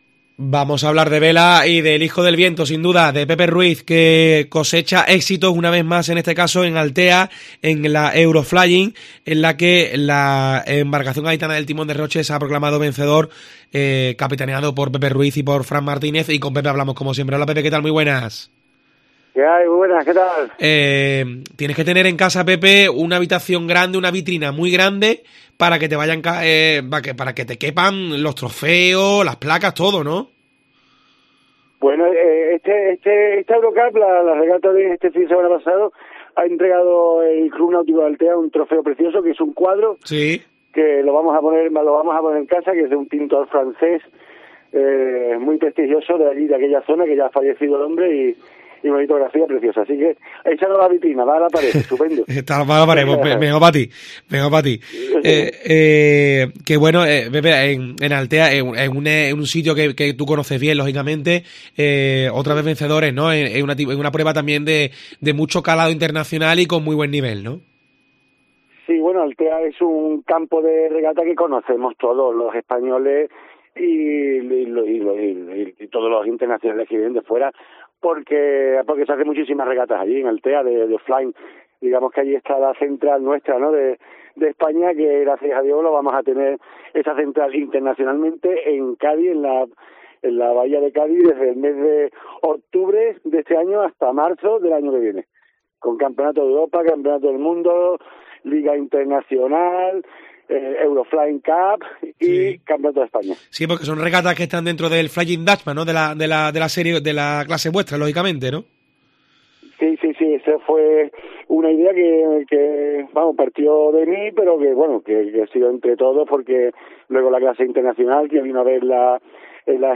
en los micrófonos de Deportes COPE